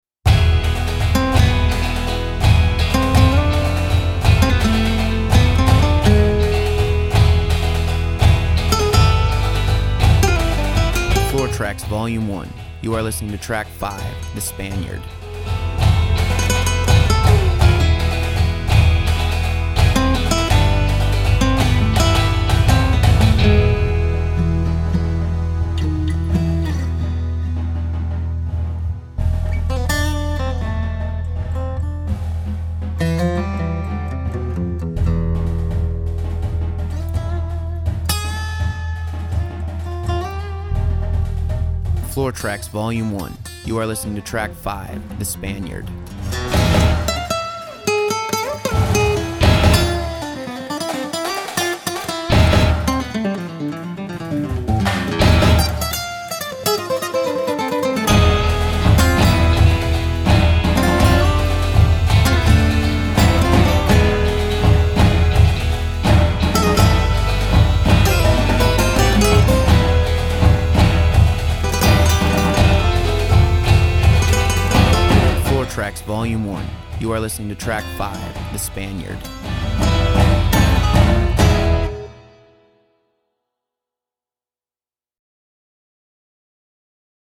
(with voiceover)   Purchase high-quality track